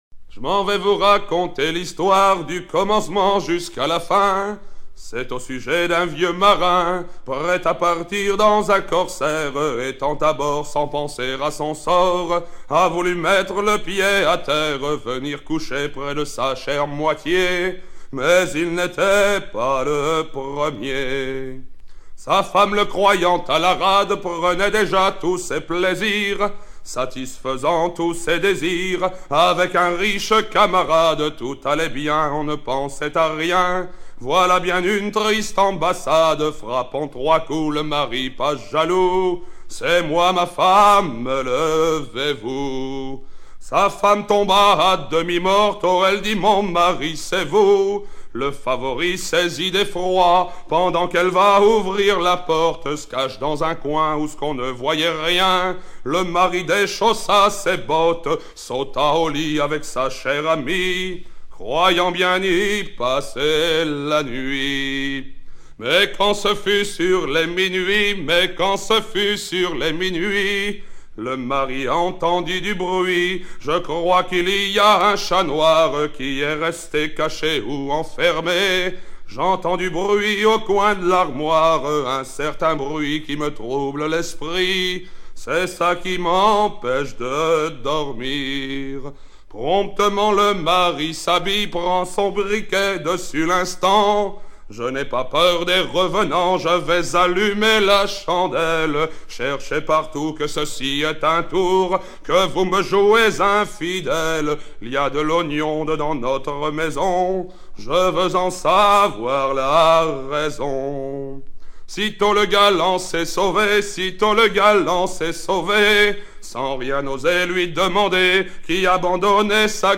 Version recueillie en 1975
Chants de marins